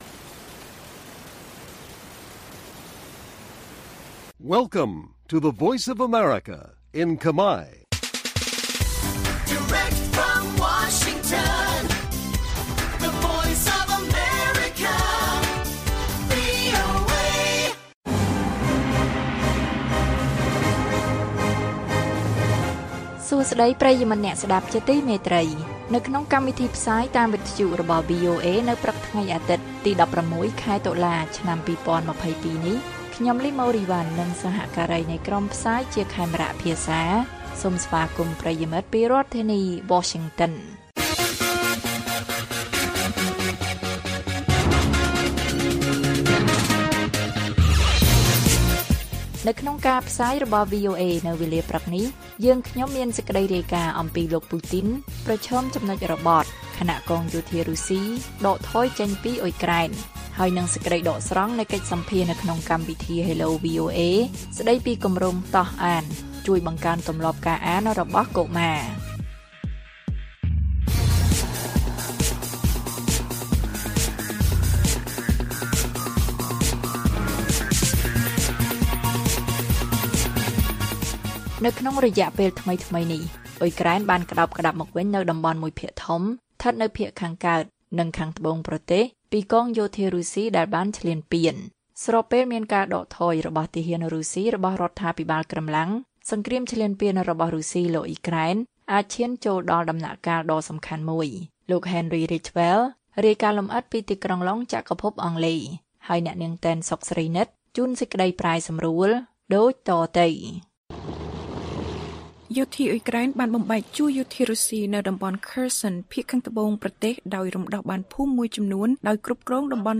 ព័ត៌មាននៅថ្ងៃនេះមាន លោក Vladimir Putin ប្រឈម«ចំណុចរបត់» ខណៈកងយោធារុស្ស៊ីដកថយចេញពីអ៊ុយក្រែន។ សេចក្តីដកស្រង់នៃបទសម្ភាសន៍នៅក្នុងកម្មវិធី Hello VOA ស្តីពីគម្រោង«តោះអាន» ជួយបង្កើនទម្លាប់ការអានរបស់កុមារ៕